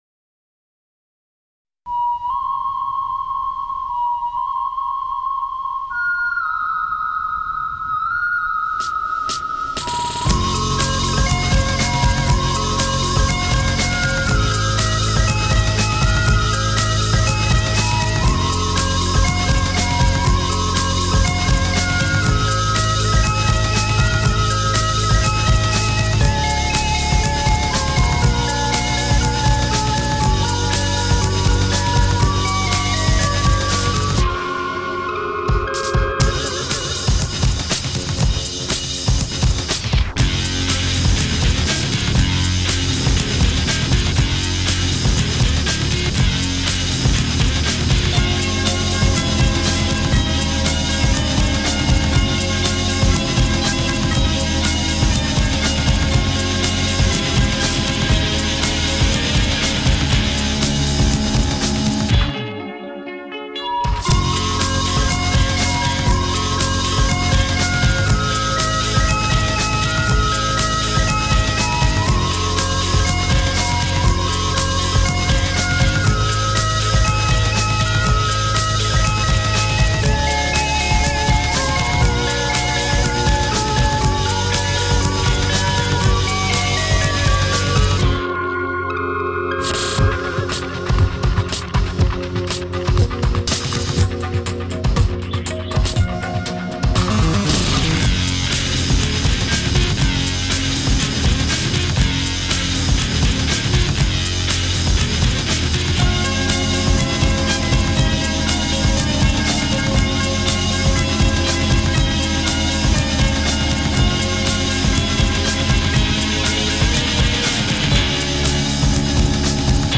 it uses the Ocarina